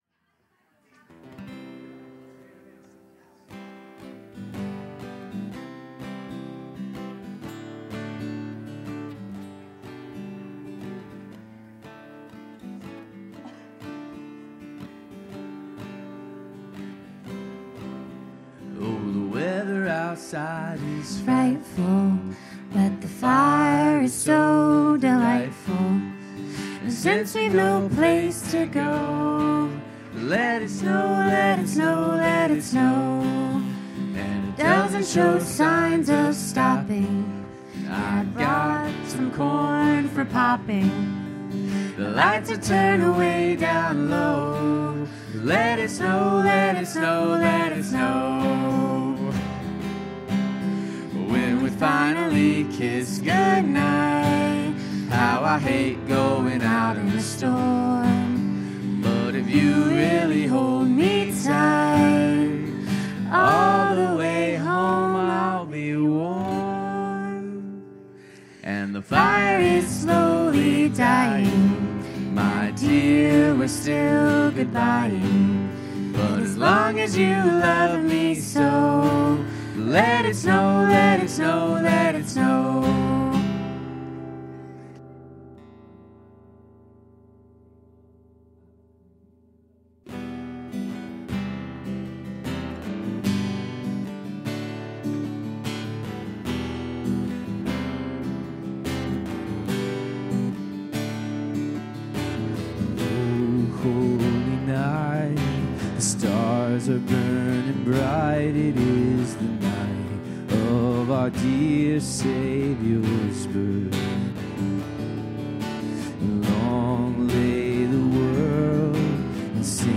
Worship 2024-12-15